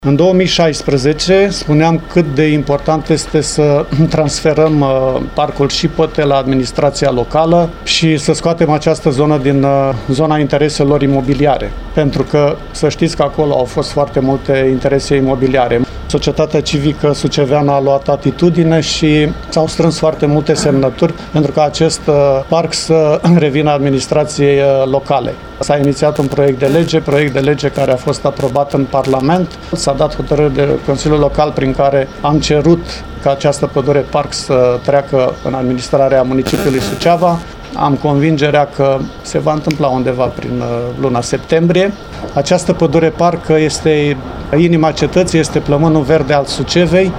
Viceprimarul MARIAN ANDRONACHE ne-a oferit o serie de amănunte despre acest subiect, spunând că principalul beneficiu al transferului parcului Șipote va fi amenajarea urbanistică fără interese imobiliare.